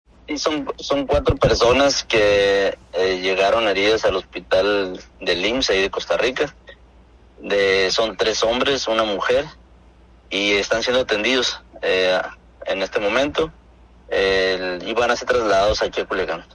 VOZ DEL SECRETARIO DE SALUD DE SINALOA, CUITLAHUAC GONZALEZ GALINDO, MENCIONA CUATRO PERSONAS HERIDAS PERO POSTERIOR A ESTA DECLARACION LLEGARON 2 MAS